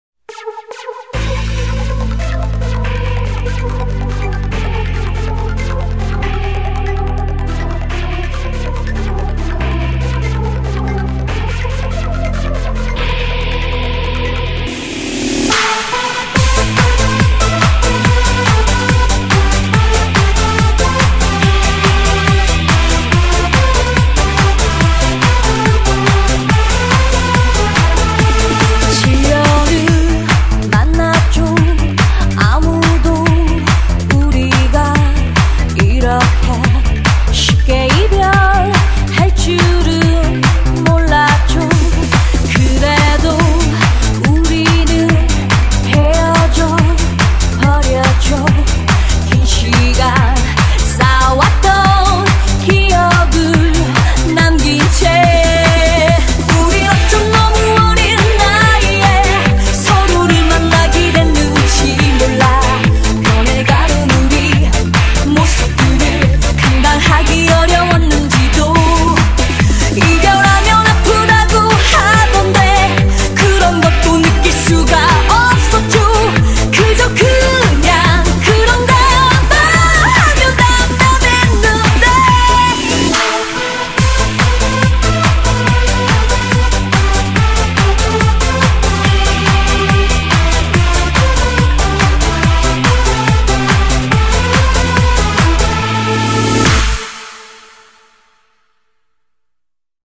BPM142--1
Audio QualityPerfect (High Quality)